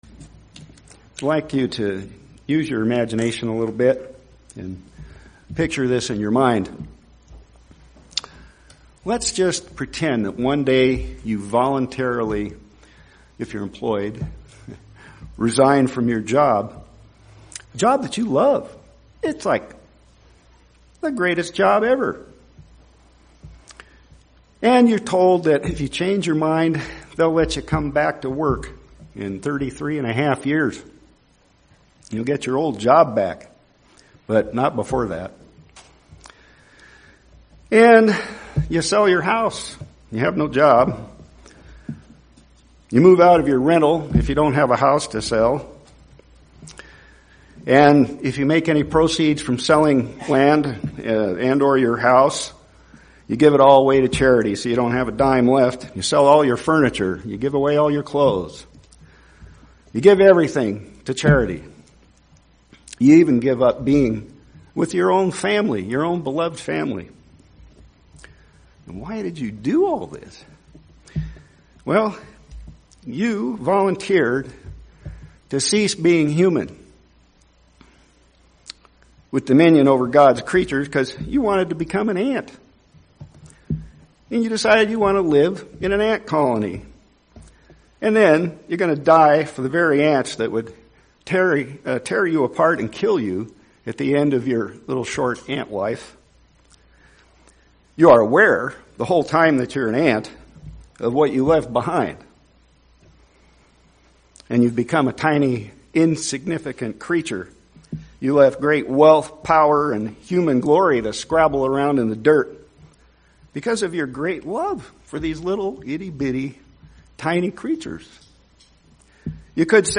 Given in Central Oregon